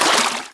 wrench_hit_liquid1.wav